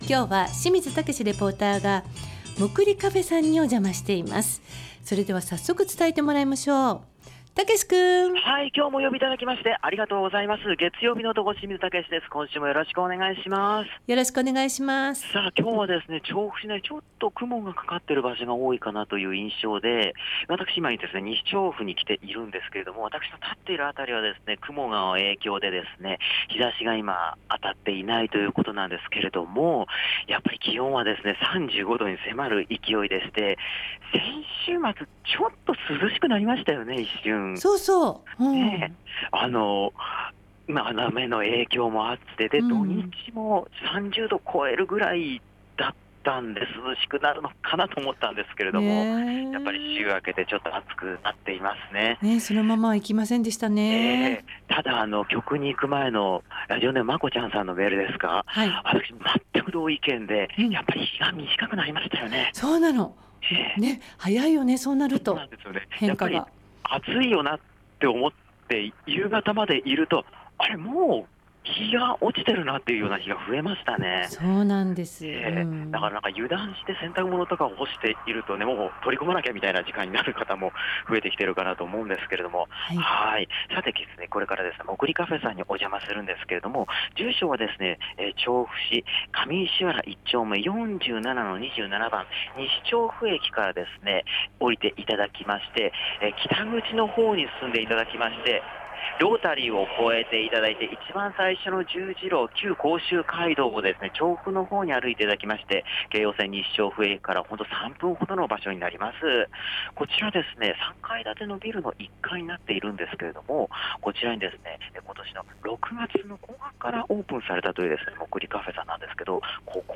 まだまだ猛暑が続く空の下からお届けした街角レポートは、6月にオープンした『モクリカフェ』さんからのレポートです！